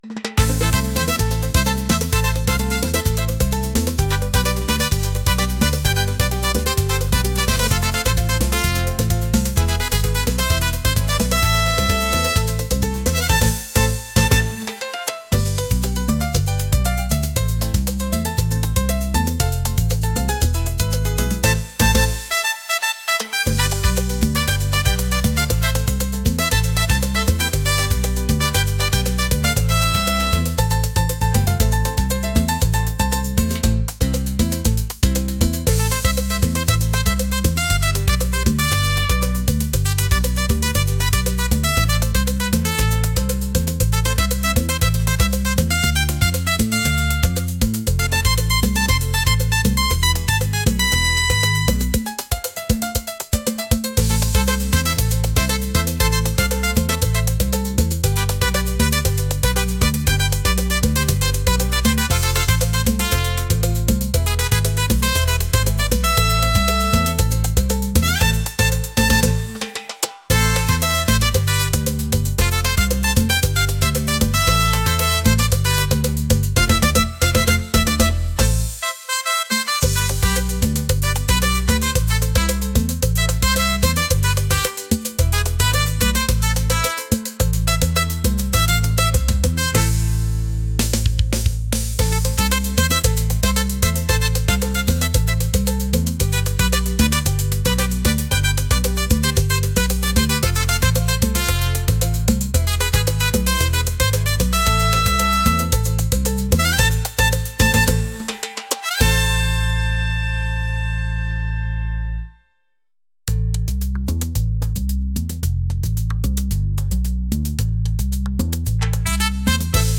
energetic | latin